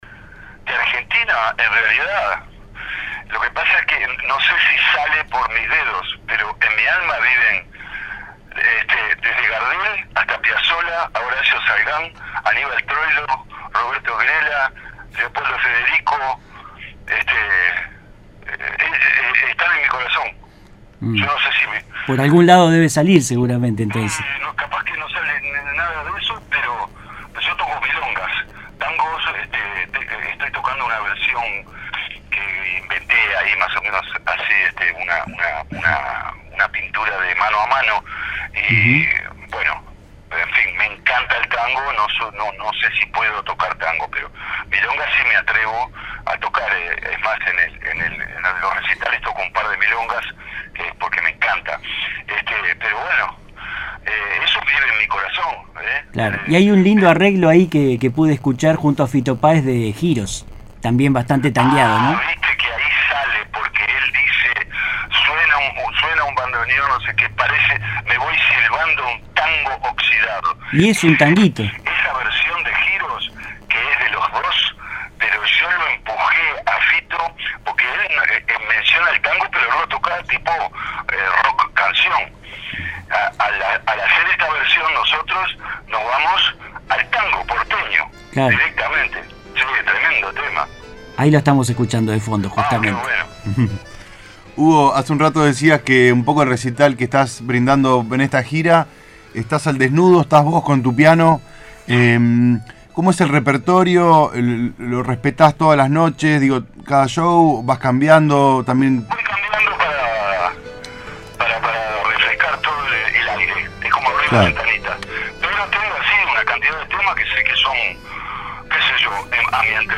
Edición-Hugo-Fattoruso-sobre-la-música-argentina-y-la-gira.mp3